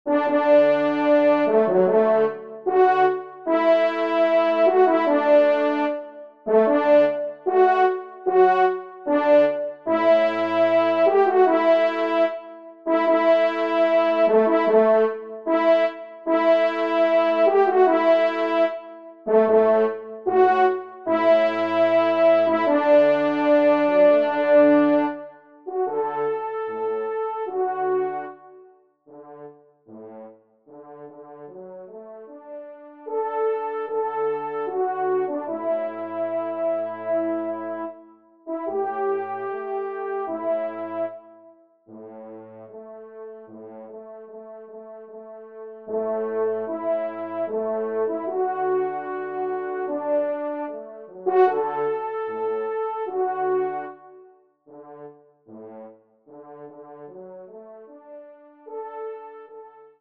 Genre :  Divertissement pour Trompes ou Cors en Ré
1e Trompe